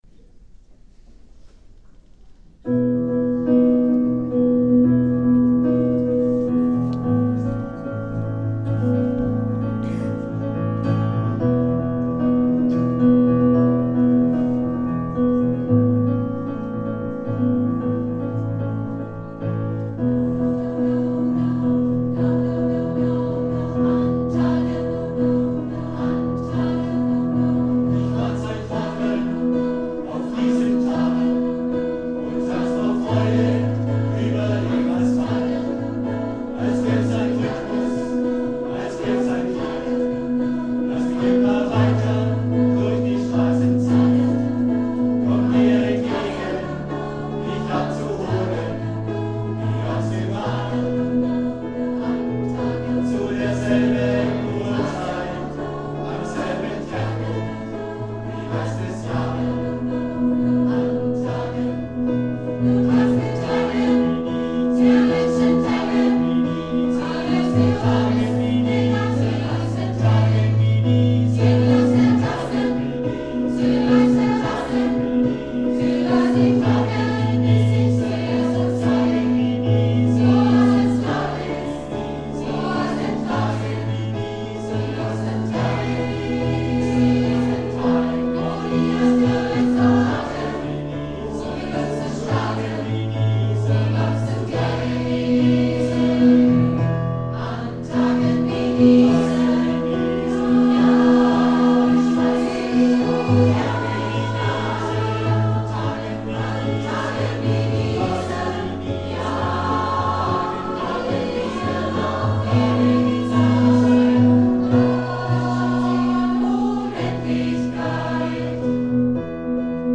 09 - Konzertaufnahmen - ChorArt zwanzigelf - Page 4
Kathy Kelly mit ChorArt zwanzigelf 17.03.2019 – Tage wie diese